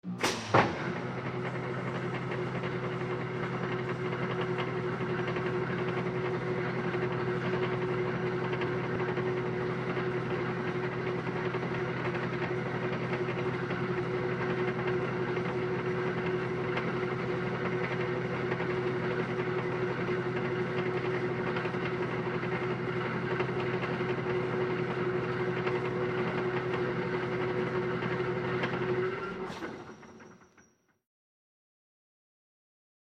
На этой странице представлены звуки башенного крана — мощные и ритмичные шумы строительной техники.
Груз медленно опускается